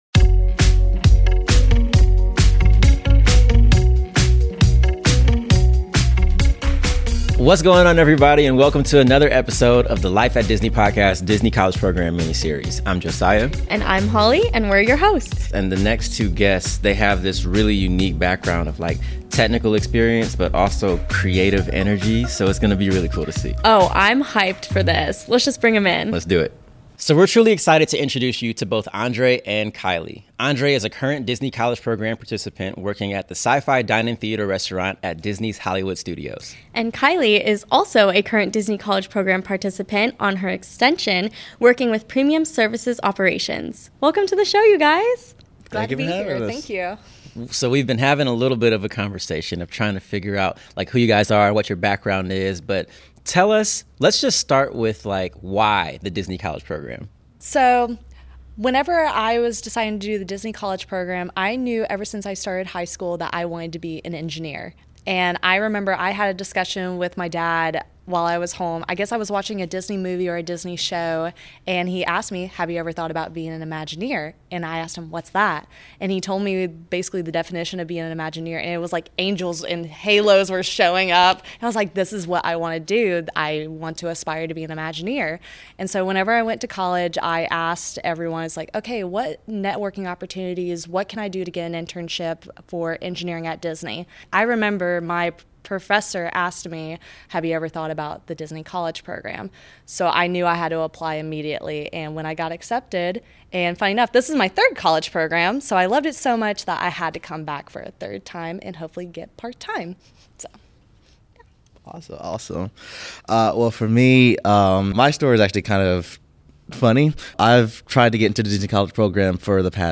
Welcome to the Life at Disney Podcast: Disney College Program Miniseries! Each season, we’ll feature current participants, alumni, recruiters and more who will join our hosts to discuss all things about life on the Disney College Program.